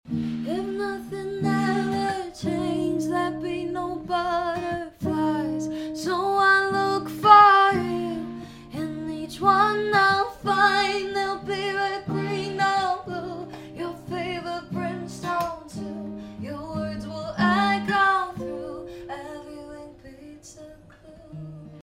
an acoustic version